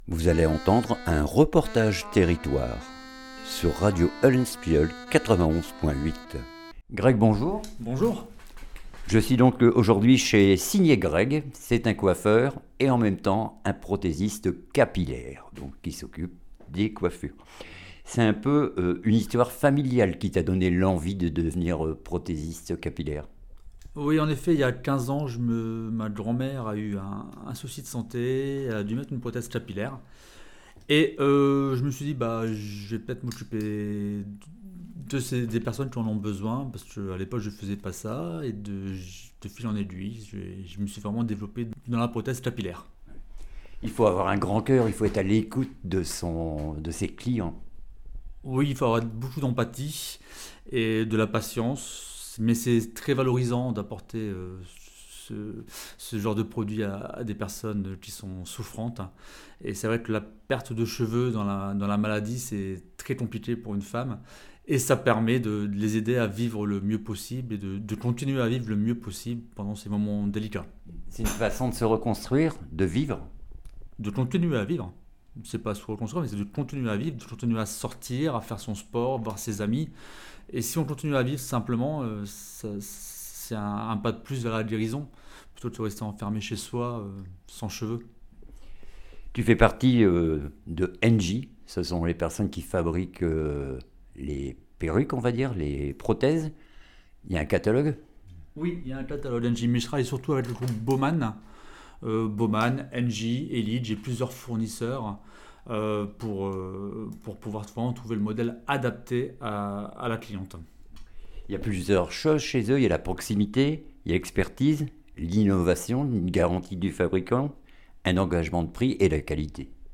REPORTAGE TERRITOIRE OCTOBRE ROSE 2025